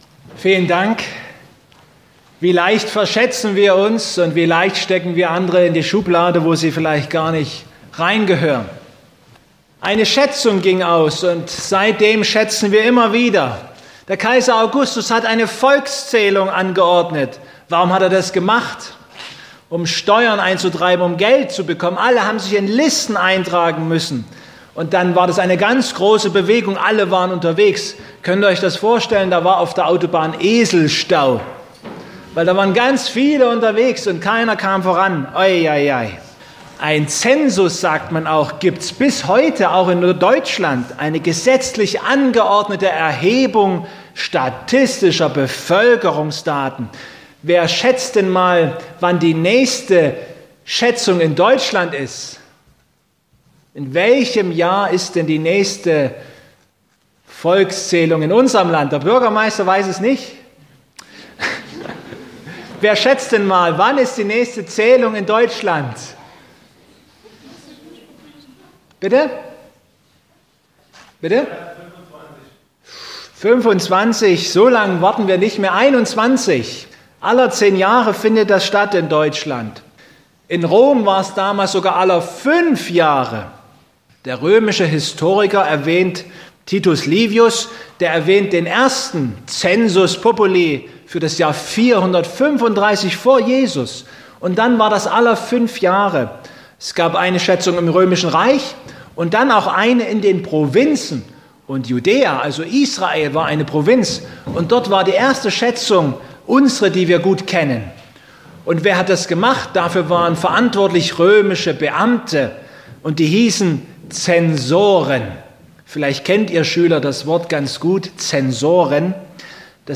Prediger: